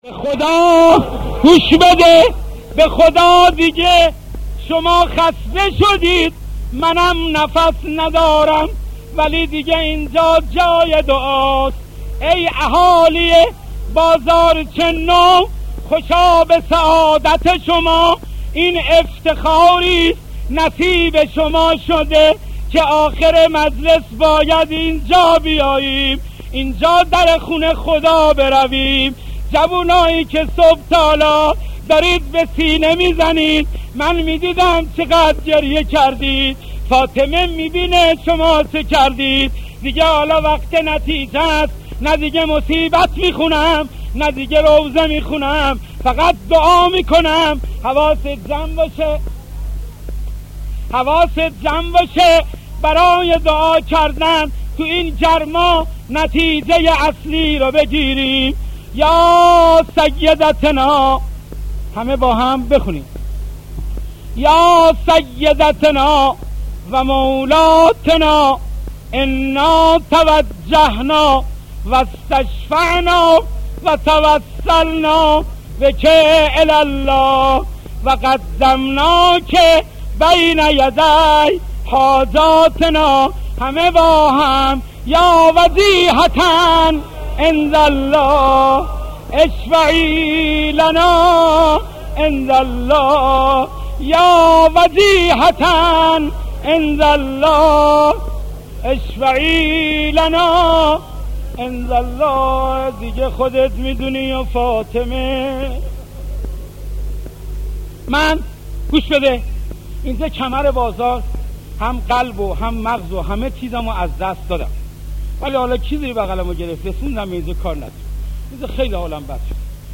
مداحی و نوحه